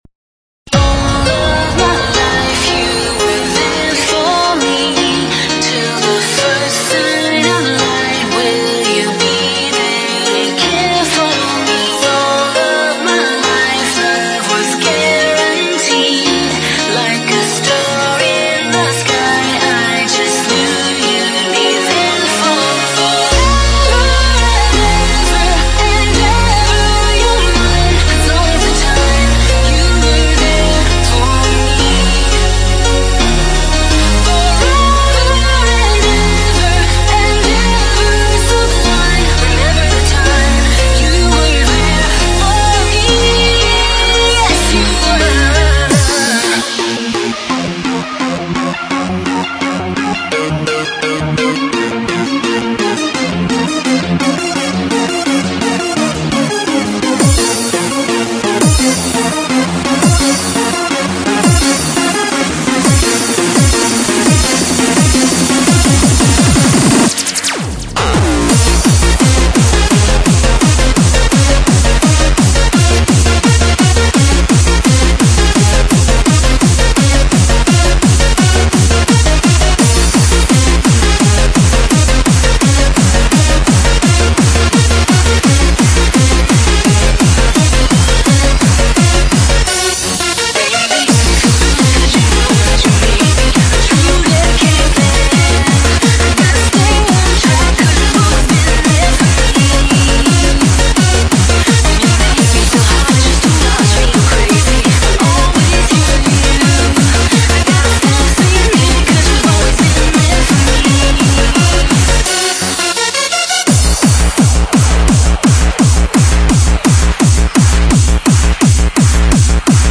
vocal-driven tracks